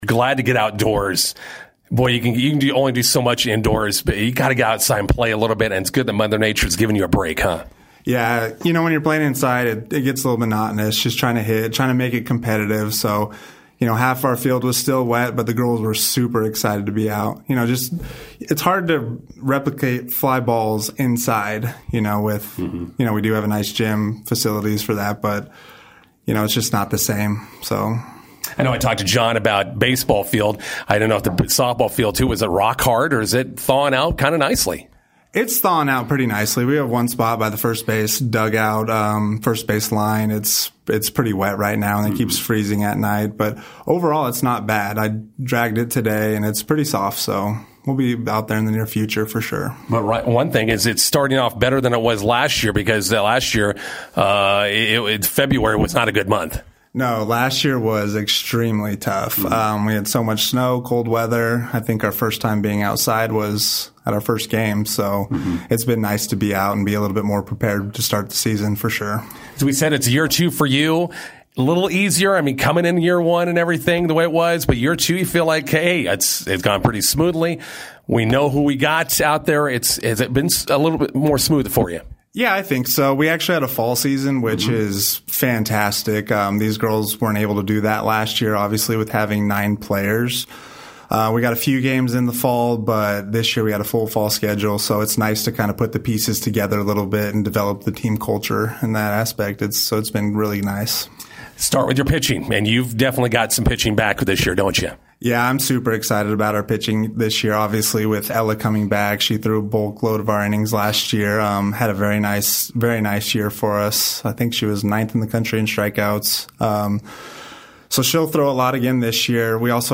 INTERVIEW: McCook Community College softball opens 2024 spring season today in Liberal, KS.